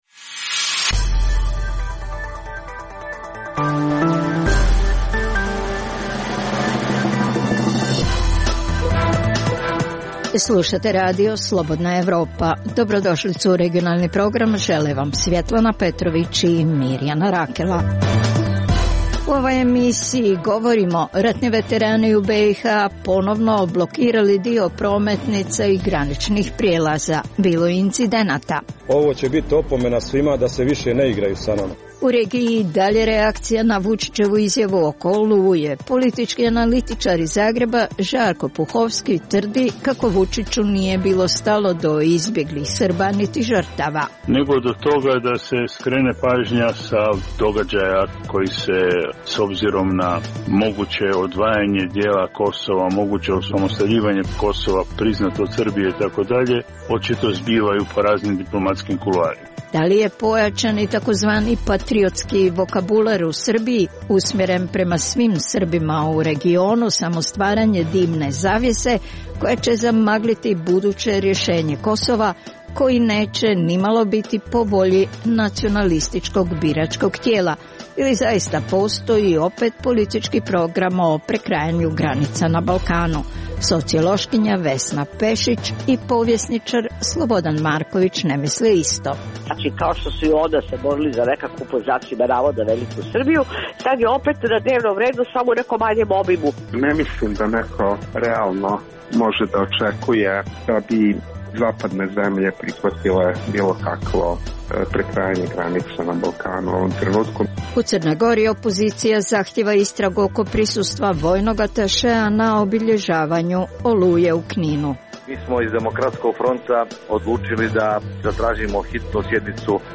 Reportaže iz svakodnevnog života ljudi su takođe sastavni dio “Dokumenata dana”.